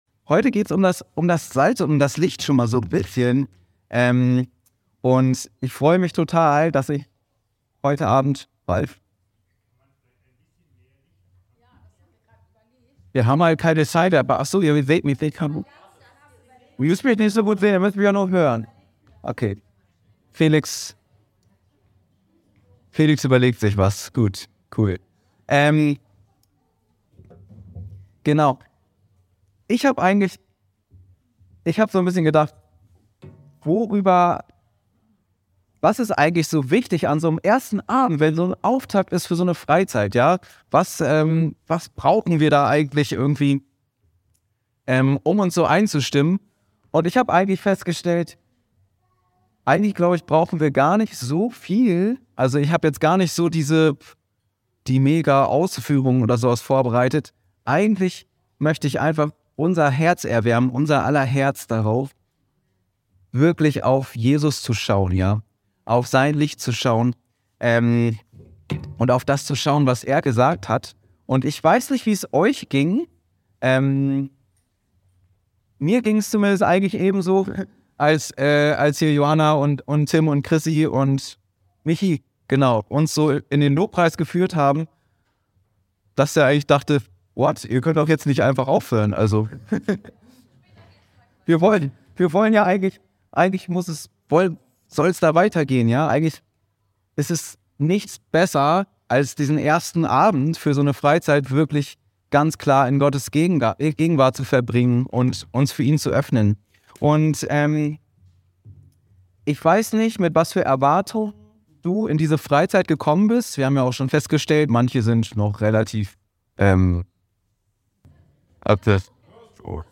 Anskar-Kirche Hamburg- Predigten